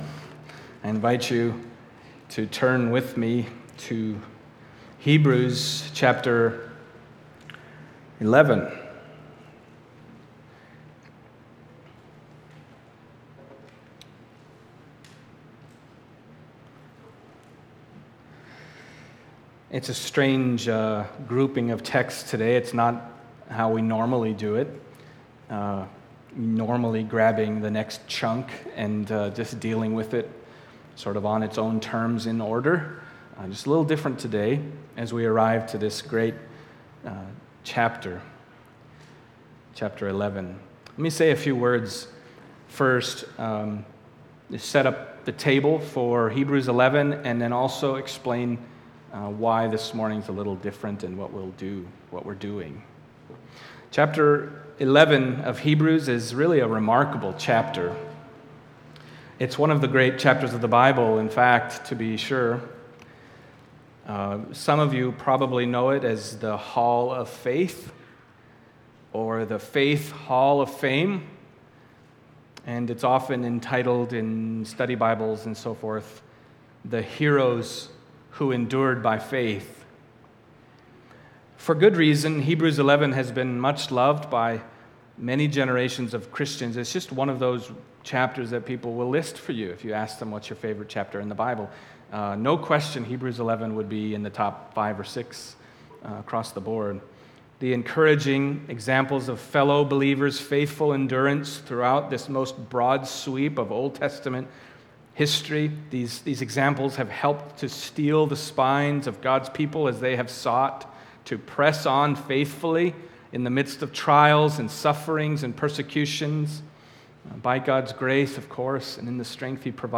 Passage: Hebrews 11:1-3 Service Type: Sunday Morning